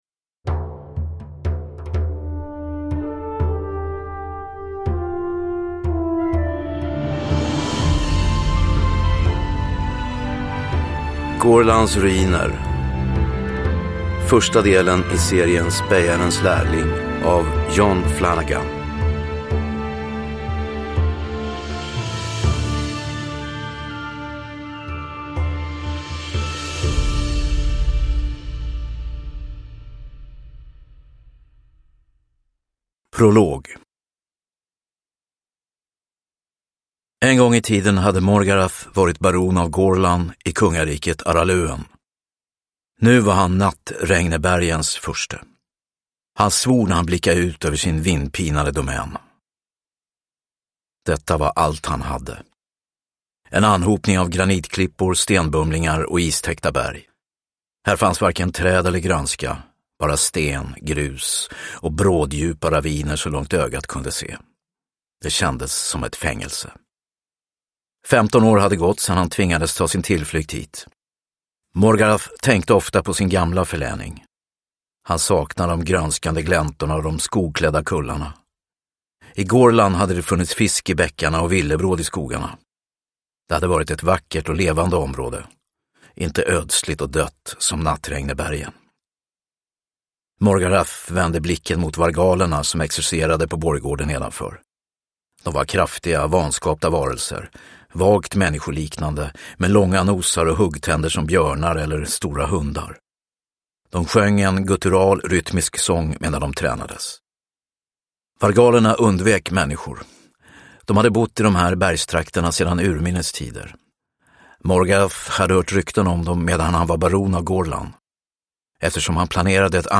Gorlans ruiner – Ljudbok – Laddas ner